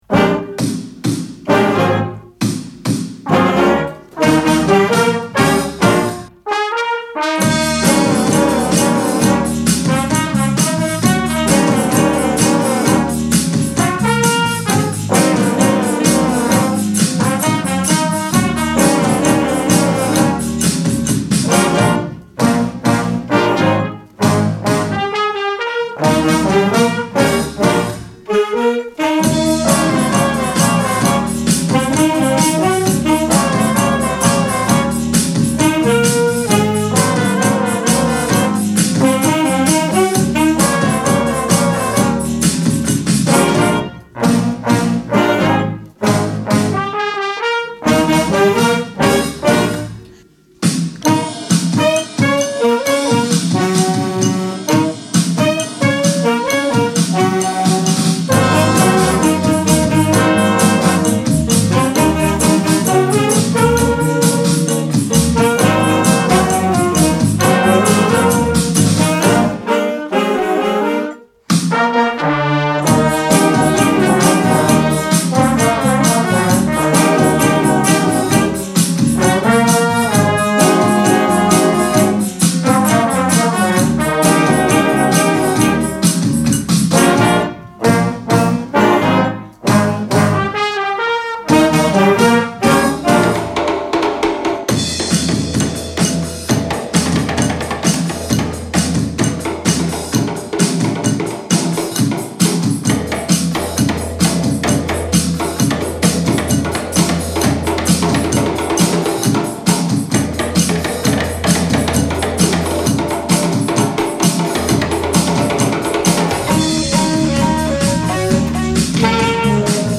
JuniorJazzBand und C.O.M.B.O waren dennoch weiterhin musikalisch aktiv im „Corona Recording Projekt“ COREPRO: Jedes Bandmitglied macht zu Hause eine Tonaufnahme seiner Instrumentalstimme eines ausgesuchten Stücks – im Fachjargon „Home-Recording“.
Wie ein Puzzle werden diese dann zu einer Gesamtaufnahme des Songs auf dem Computer zusammengefügt.
I Feel Good – JuniorJazzBand